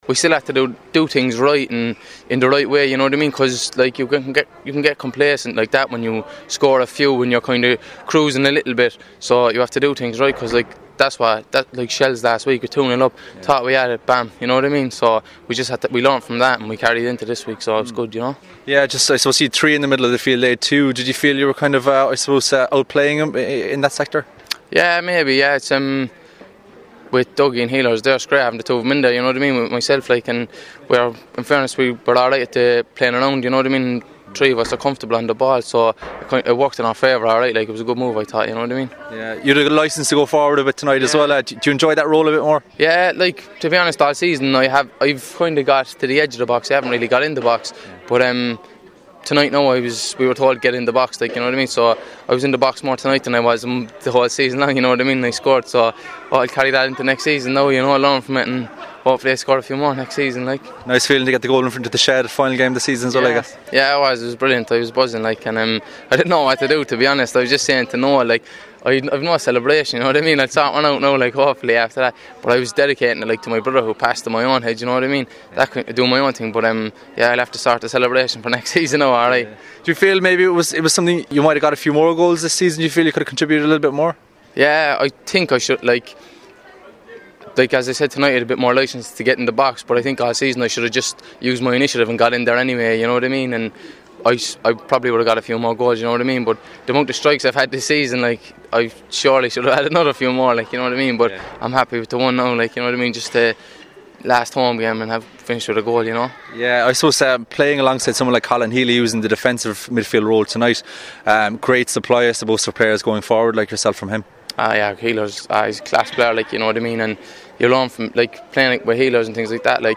speaking after their 3-0 win over Dundalk